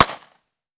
9mm Micro Pistol - Gunshot A 002.wav